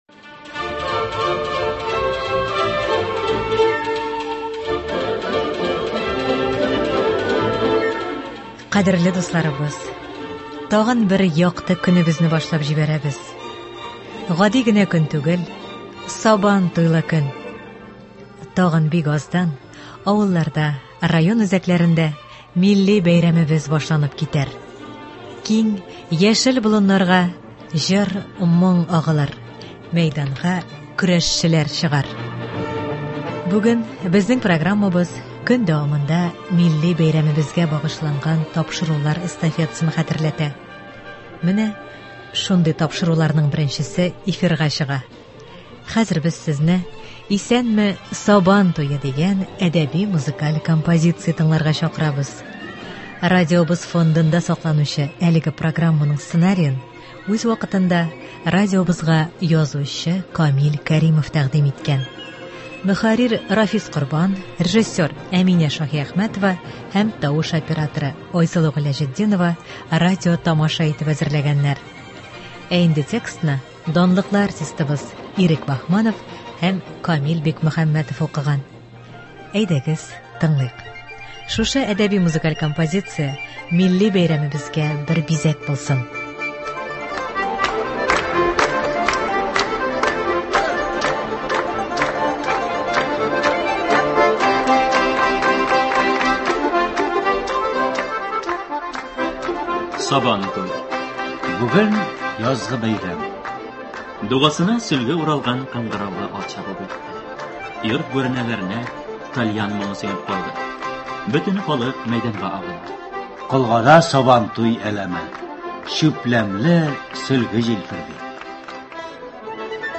Хәзер без сезне «Исәнме, Сабан туе!» дигән әдәби-музыкаль композиция тыңларга чакырабыз.